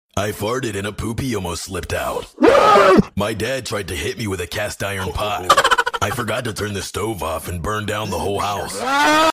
I Farted And A Poopy Almost Slipped Out